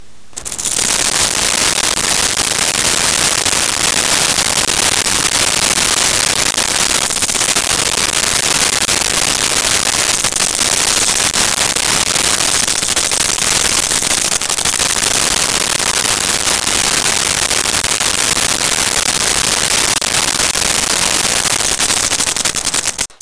Snake Rattle
Snake Rattle.wav